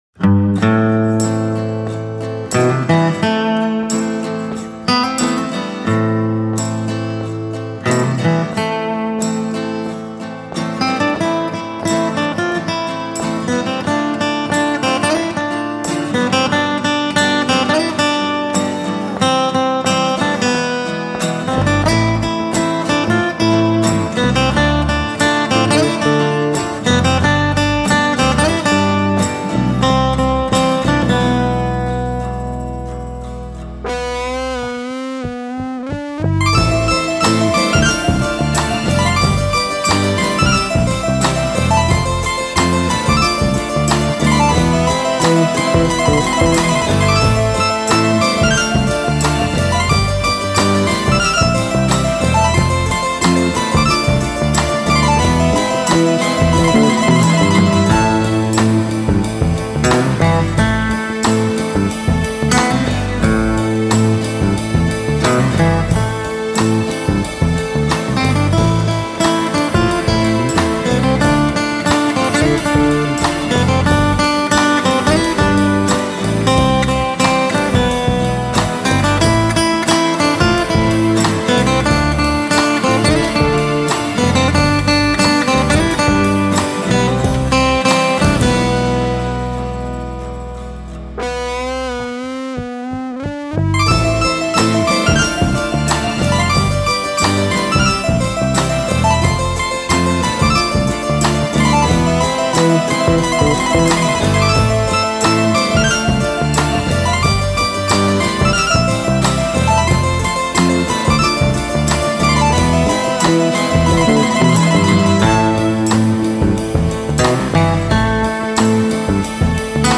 instrumental-naya-muzyka-krasivaya-melodiya-4-2.mp3